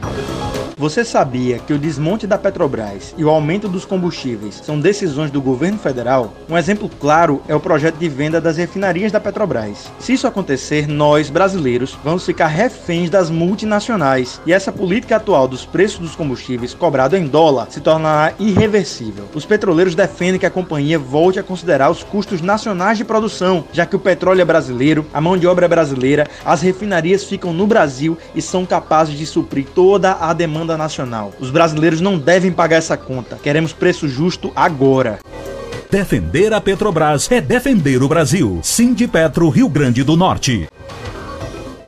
Nas rádios o sindicato está veiculando spot’s sobre o assunto e fazendo sorteios de voucheres de gasolina no valor de R$50,00.